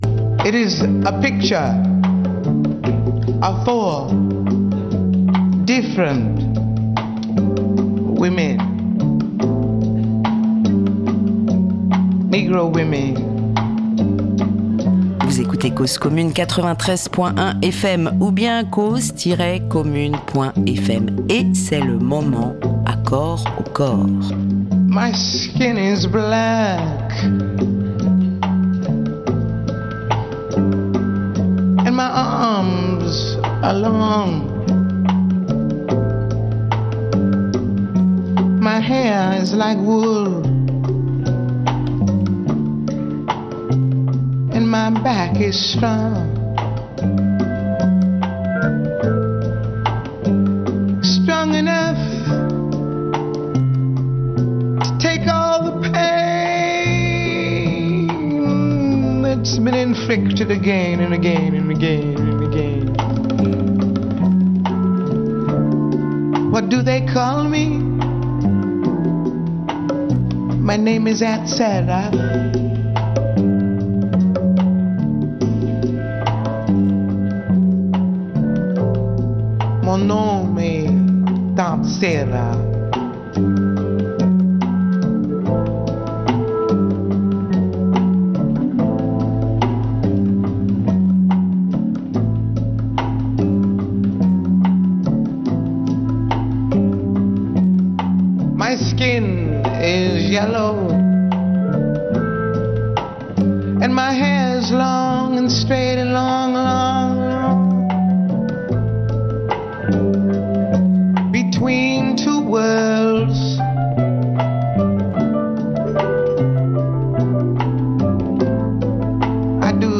Dans cette seconde partie, les prises de paroles se poursuivent et racontent, parlent d’elles-mêmes, à cœur irréversiblement ouvert.